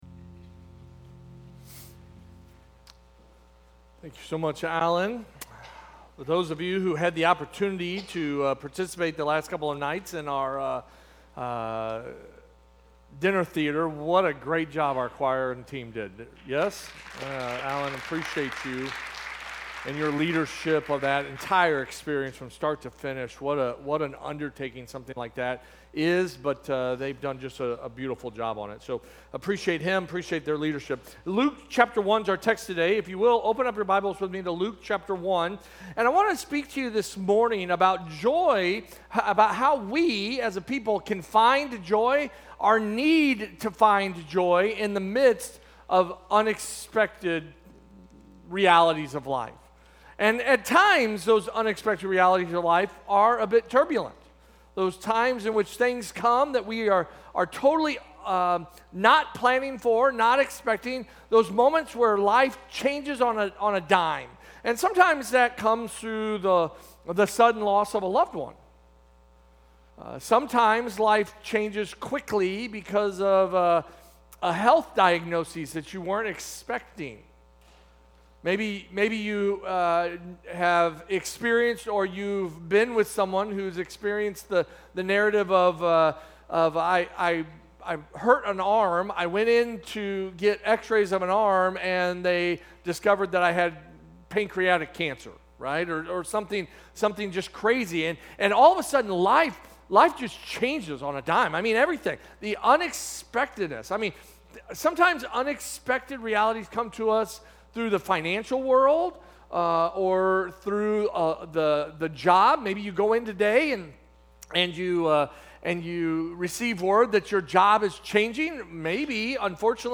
Sermons - First Baptist Church O'Fallon First Baptist Church O'Fallon